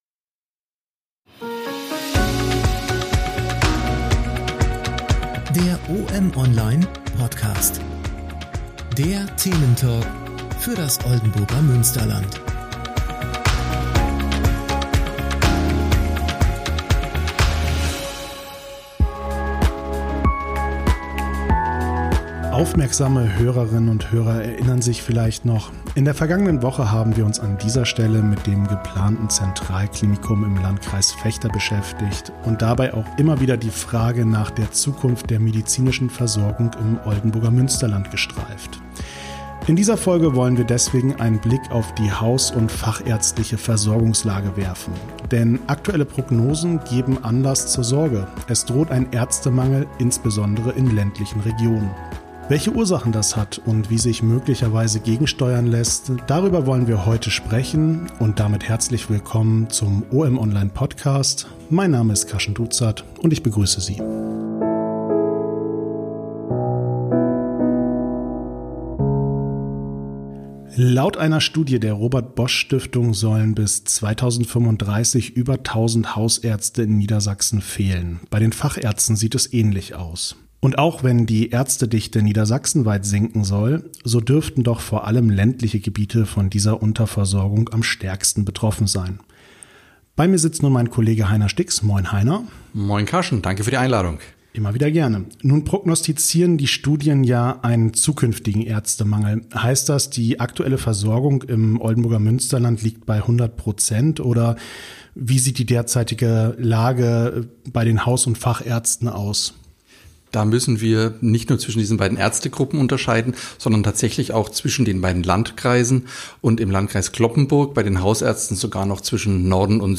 Der Thementalk für das Oldenburger Münsterland: In der 12 Folge geht es über den Mangel an Haus- und Fachärzten im ländlichen Raum, der sich in Niedersachsen, verschiedenen Studien zufolge, in den kommenden Jahren noch verschärfen dürfte.